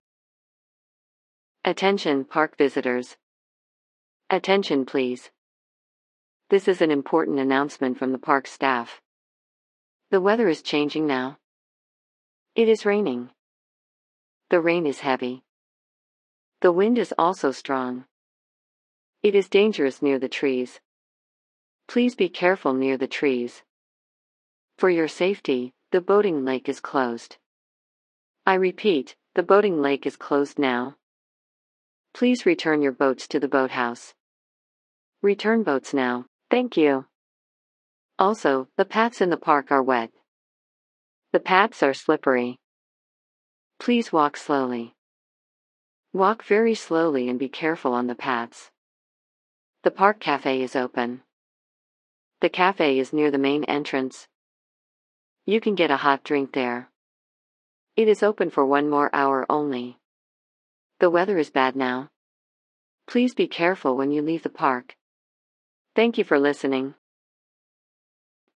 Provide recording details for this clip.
An announcement in a park warns visitors about bad weather and gives safety instructions.